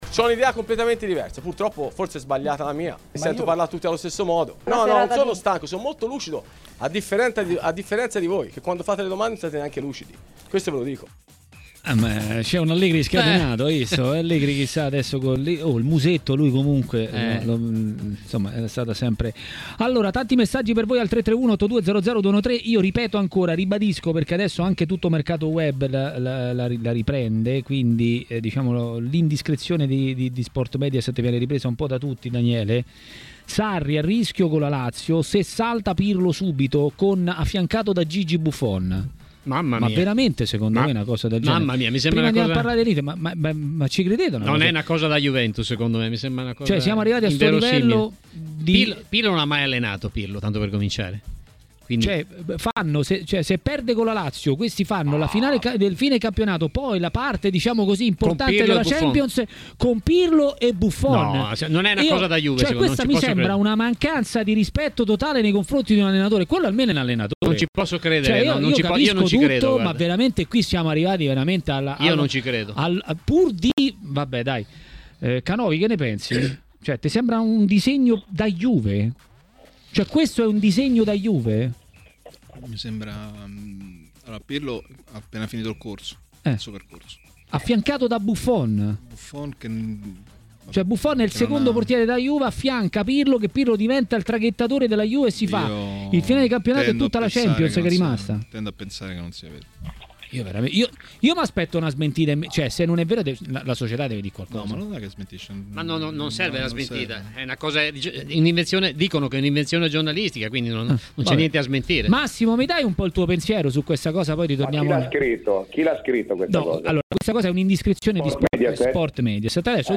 è stato intervistato da Tmw Radio , nel corso di Maracanà.